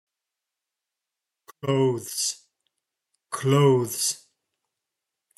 How to pronounce clothes